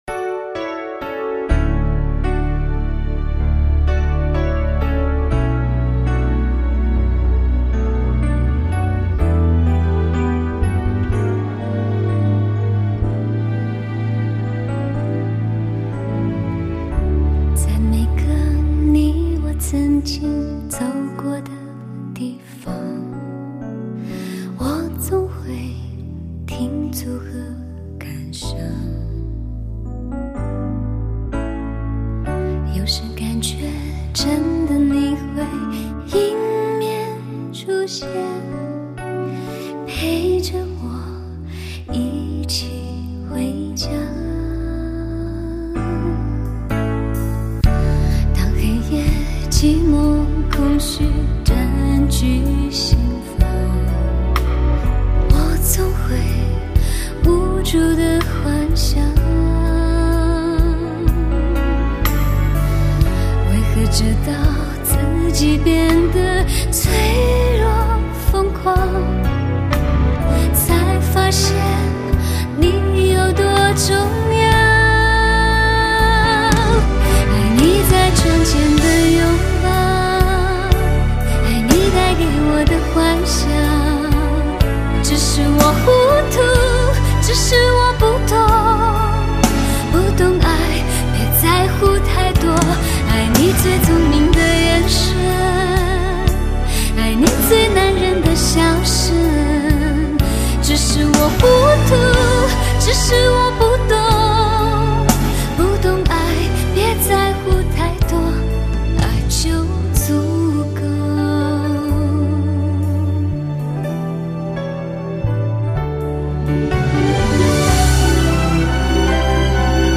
音乐风格清新中带着成长，更多了一些成熟、时尚的味道。